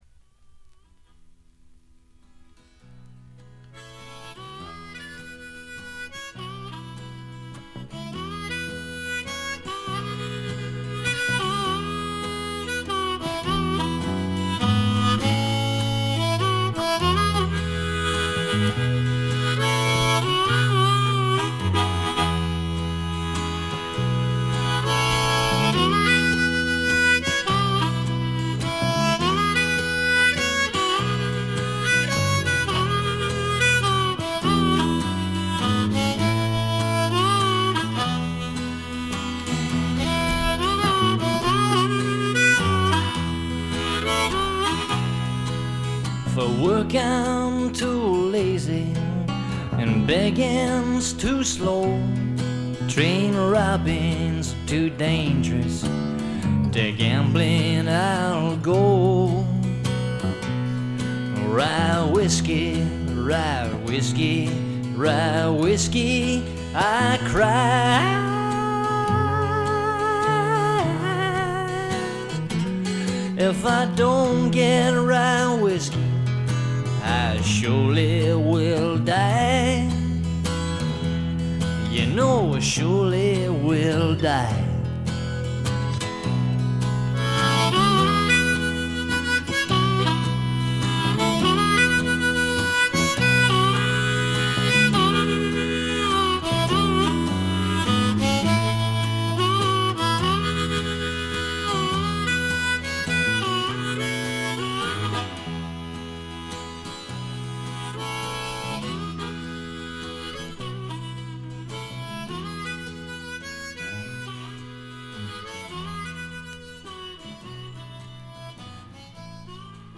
気付いたのはこれぐらいで、ほとんどノイズ感無し。
試聴曲は現品からの取り込み音源です。